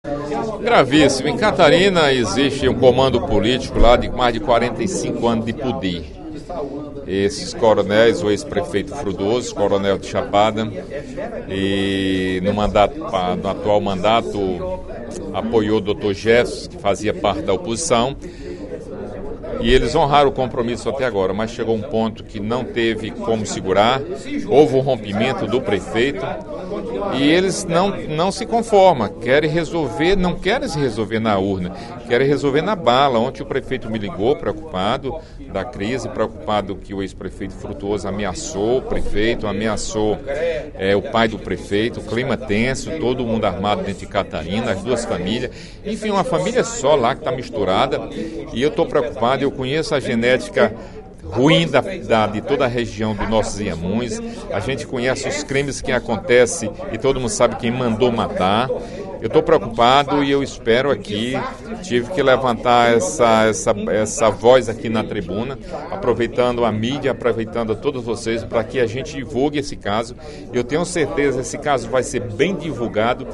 O deputado Perboyre Diógenes (PMDB) alertou nesta quinta-feira (24/05), durante pronunciamento, para o “clima tenso” no município de Catarina em razão das eleições municipais deste ano.